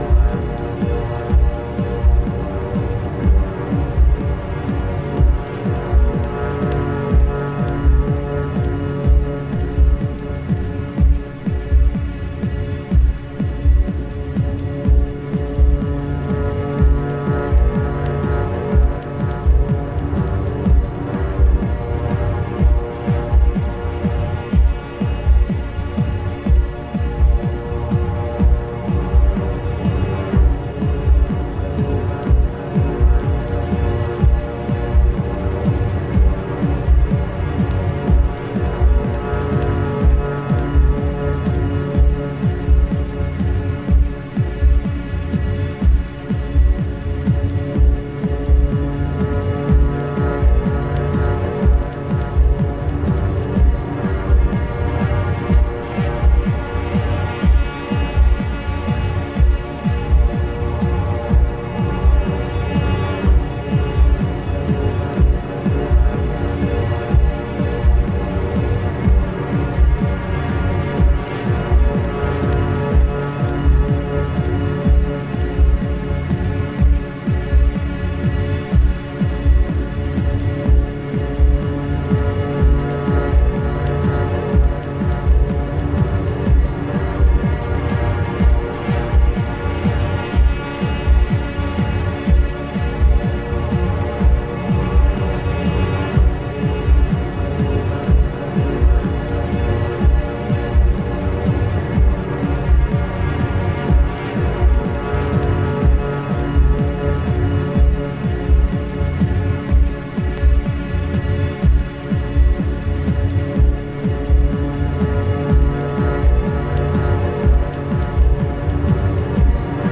骨組みだけで構成されたミニマルとはまた違ったドローニッシュなサウンド。
拡散、雲散するシンフォニックなレイヤーがじんわりと入ってきてじんわりと抜けていきます。